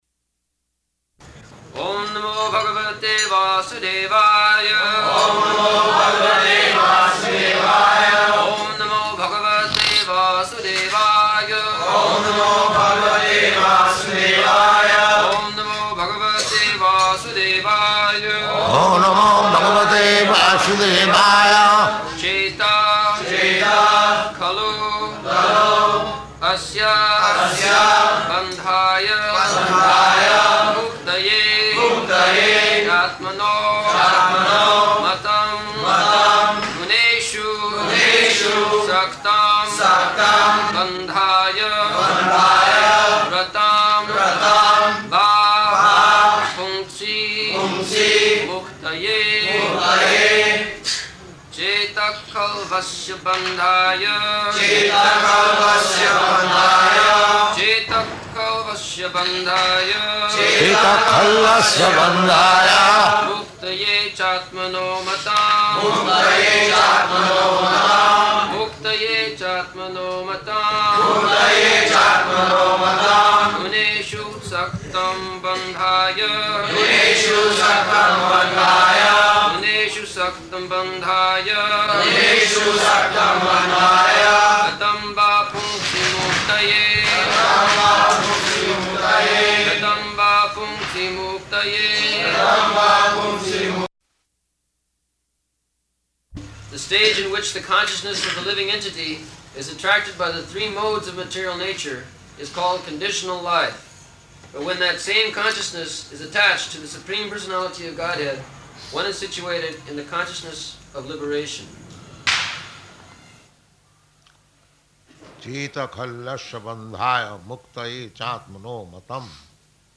November 15th 1974 Location: Bombay Audio file
[loud noise of fireworks in background]
[Prabhupāda and devotees repeat]